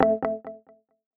HTC Desire Bildirim Sesleri